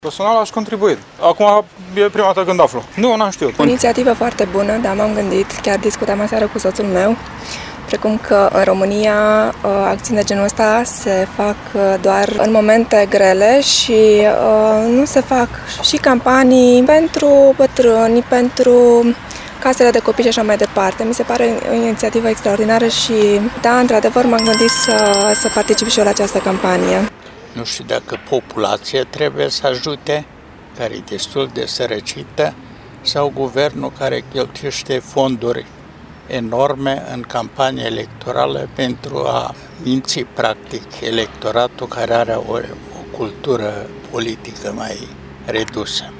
voxuri-ajutoare.mp3